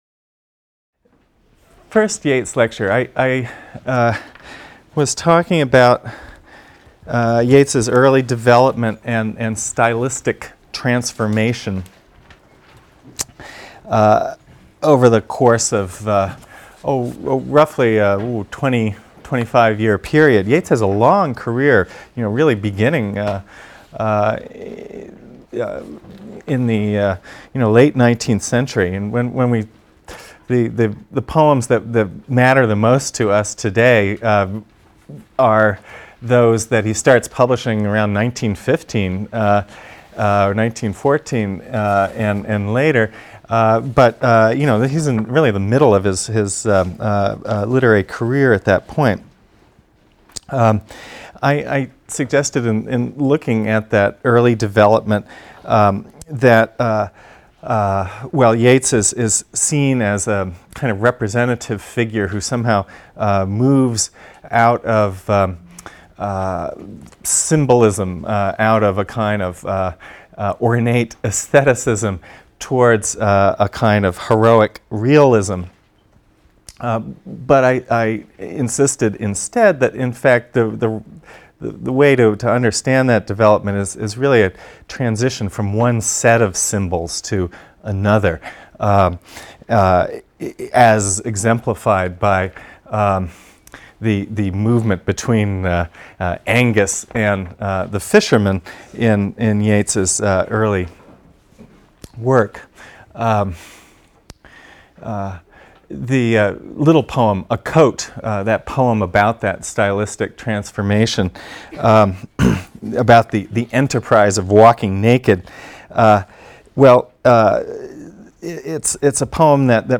ENGL 310 - Lecture 5 - William Butler Yeats (cont.)